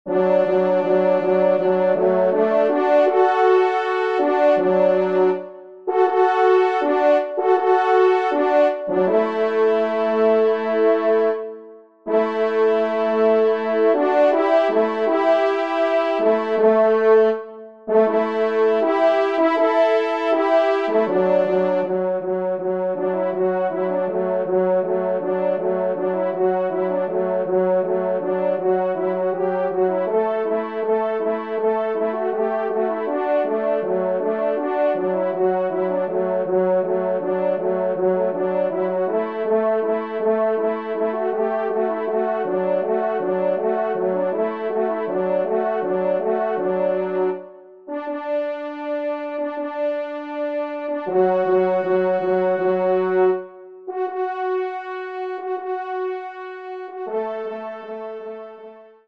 Genre :  Divertissement pour Trompes ou Cors en Ré
2e Trompe